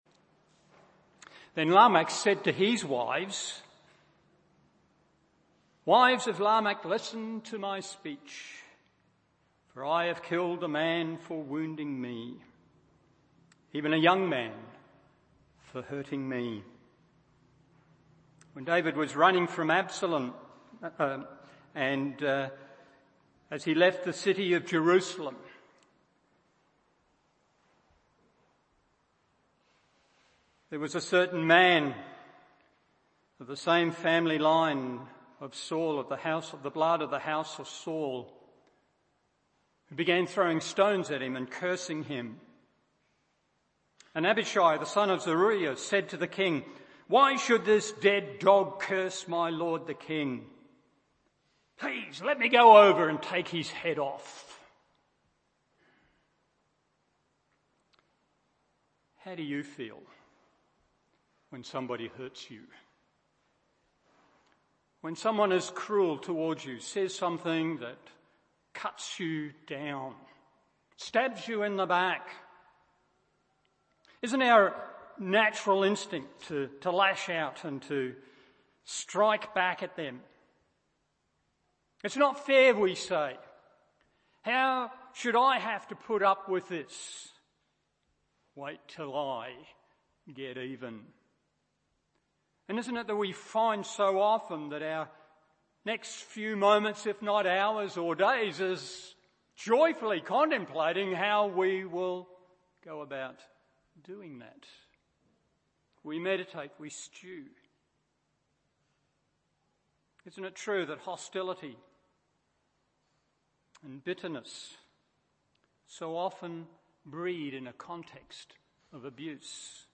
Morning Service Matt 5:38-42 1.